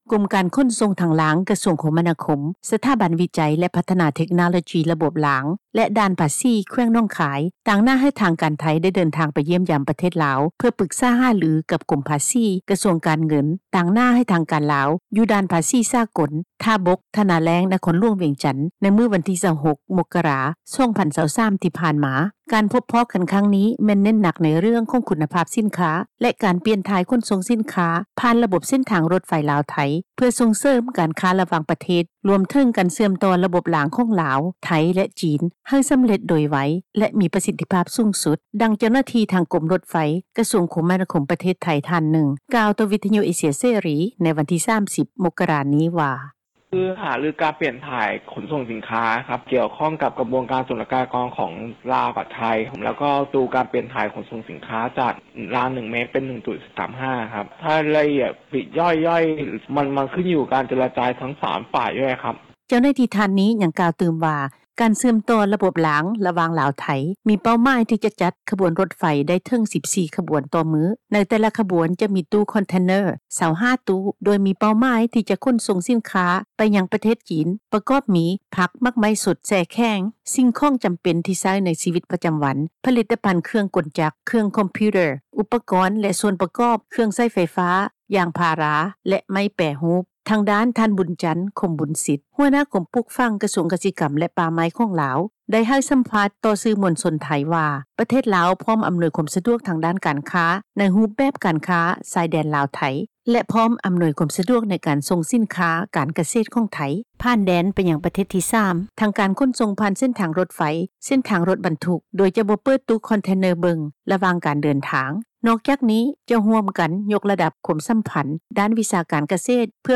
ດັ່ງ ເຈົ້າໜ້າທີ່ກົມທາງຣາງຣົຖໄຟ ກະຊວງຄົມນາຄົມ ປະເທດໄທຍ ທ່ານນຶ່ງ ກ່າວຕໍ່ວິທຍຸ ເອເຊັຽເສຣີ ໃນມື້ວັນທີ 30 ມົກຣາ ນີ້ວ່າ:
ດັ່ງ ເຈົ້າໜ້າທີ່ ທີ່ກ່ຽວຂ້ອງກັບວຽກງານ ຂົນສົ່ງ ຝ່າຍທາງຣົຖໄຟ ກ່າວຕໍ່ວິທຍຸ ເອເຊັຽເສຣີ ໃນມື້ດຽວກັນນີ້ວ່າ: